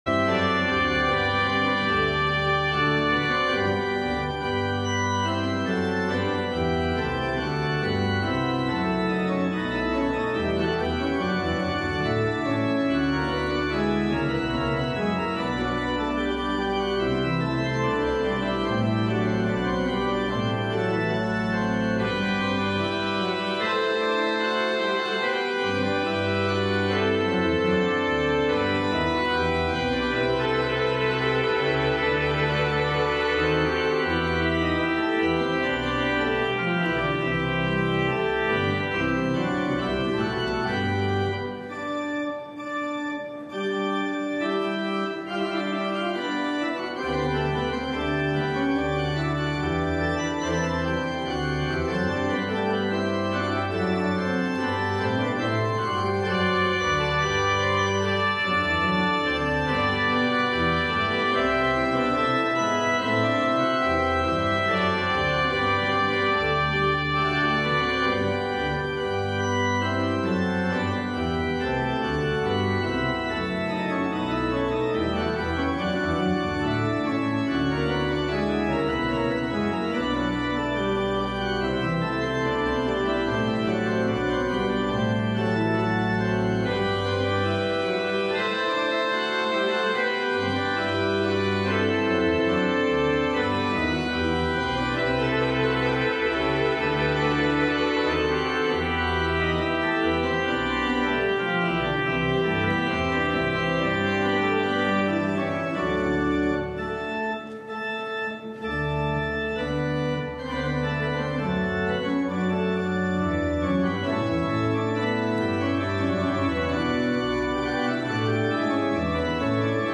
LIVE Evening Worship Service - Thanksgiving Hymn Sign
Congregational singing—of both traditional hymns and newer ones—is typically supported by our pipe organ.